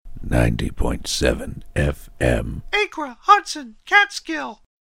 Official station identification